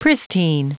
Prononciation du mot pristine en anglais (fichier audio)
Prononciation du mot : pristine